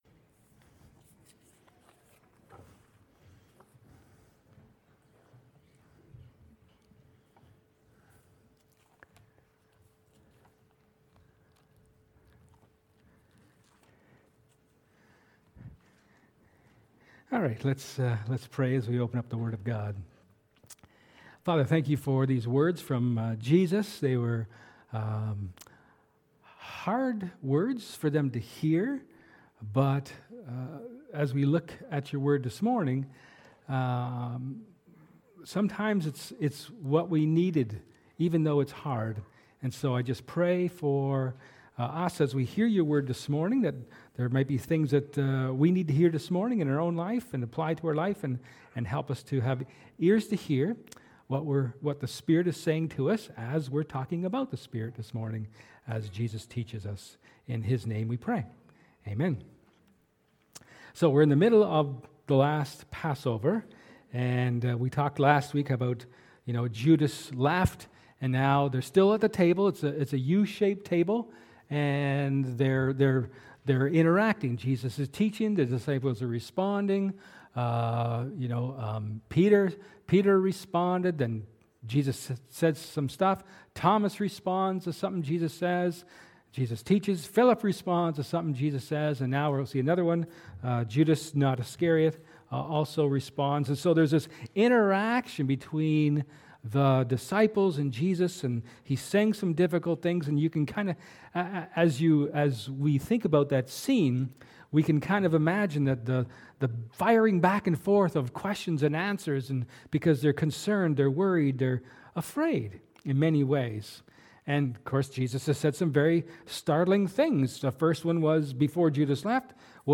March-27th-sermon.mp3